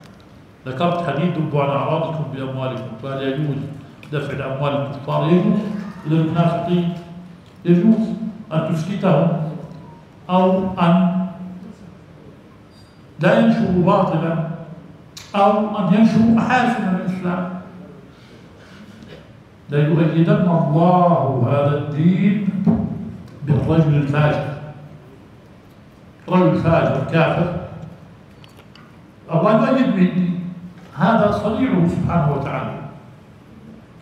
البث المباشر – لدرس شيخنا شرح صحيح مسلم